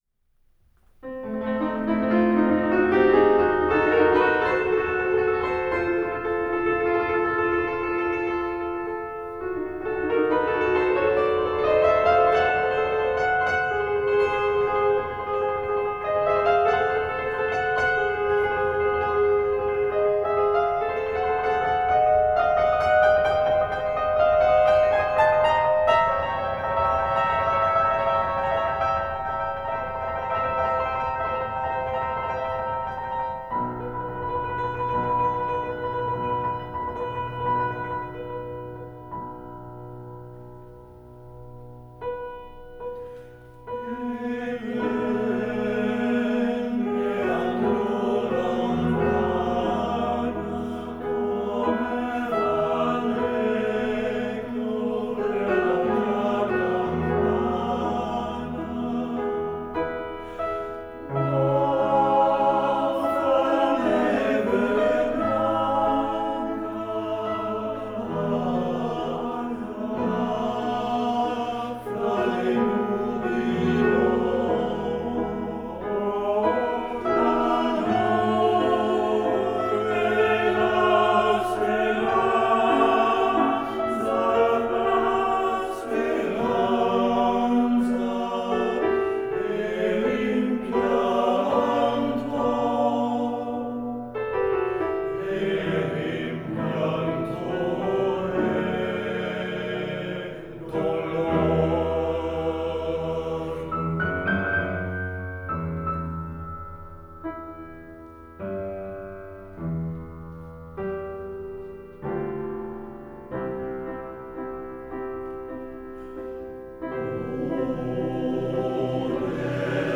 Trotz erkältungsbedingter Unlust lohnte sich am Abend der Besuch der Chorprobe, denn wir probten mein absolutes Lieblingsstück: „Ebben? Ne andrò lontana“ aus der Oper „La Wally“ von Alfredo Catalani, bekannt geworden unter anderem durch Maria Callas.
Ist das nicht wunderwunderschön?